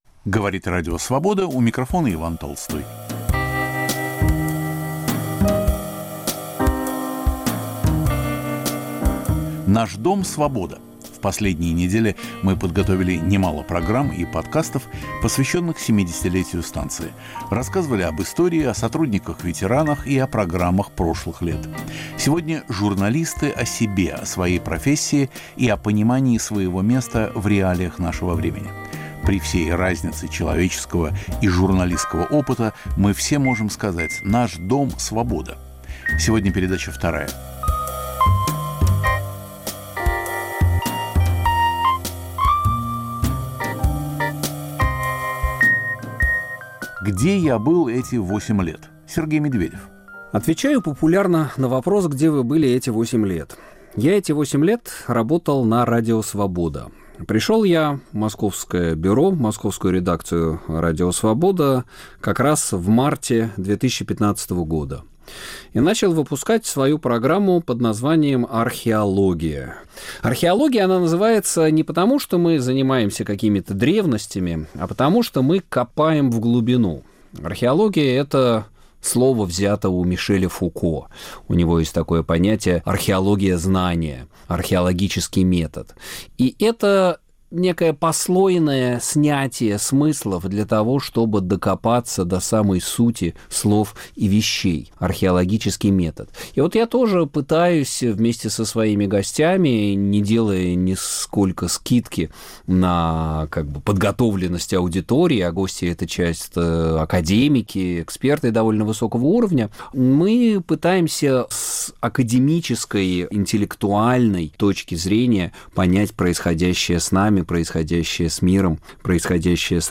Наш дом – "Свобода". Говорят сотрудники редакции.